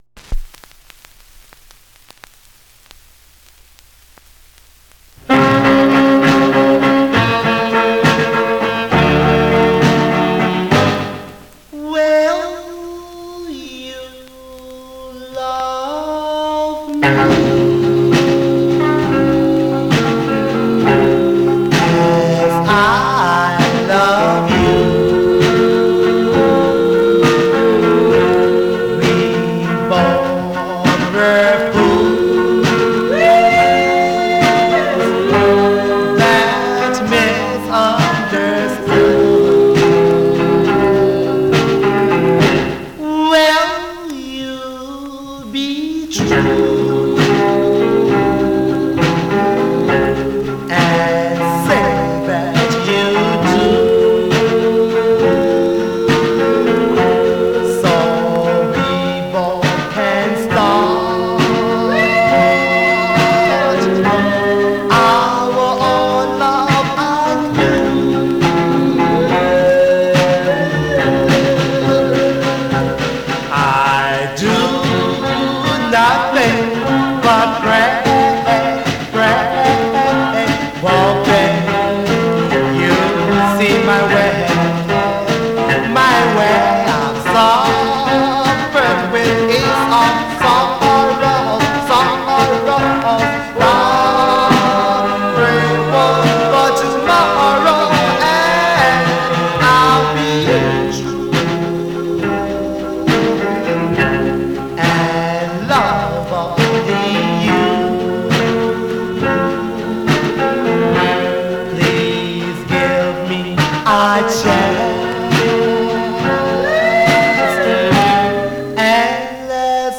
Surface noise/wear Stereo/mono Mono
Male Black Group